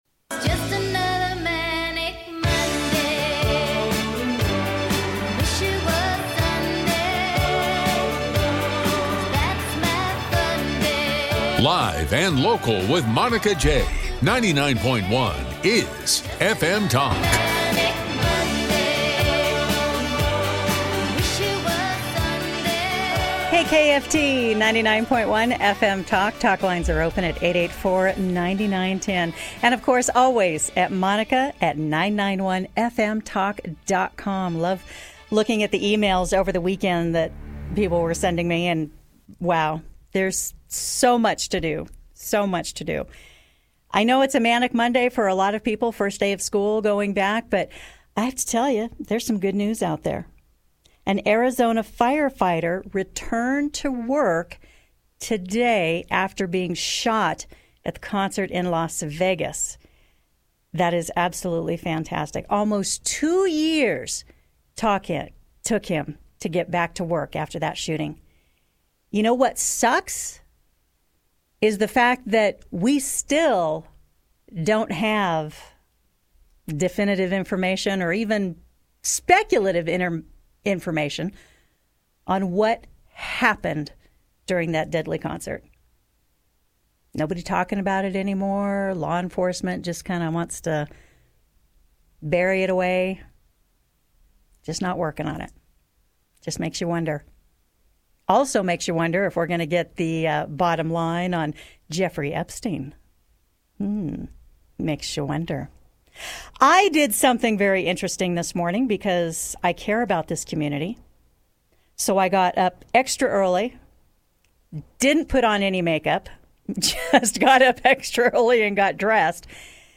The Washoe County School District is discussed in great detail with a lot of input and opinions from callers.